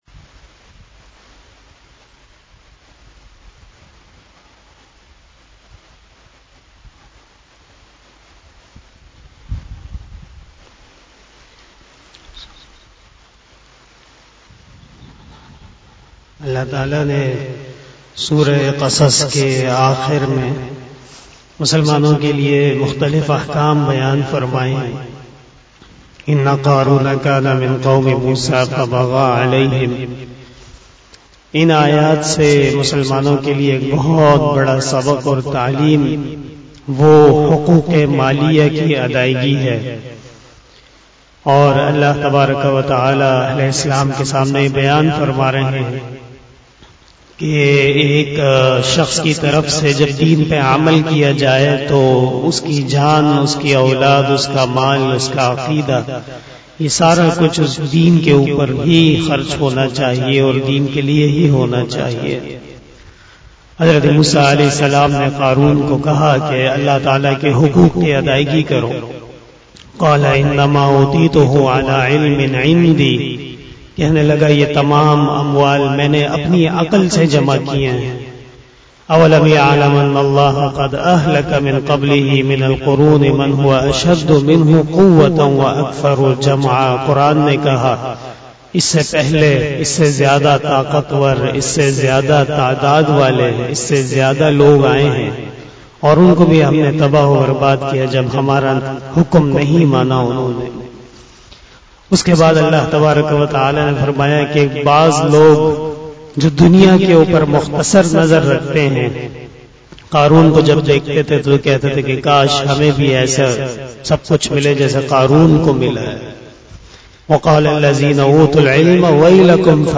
068 After fjar Namaz Bayan 05 October 2021 (27 Safar 1443HJ) Tuesday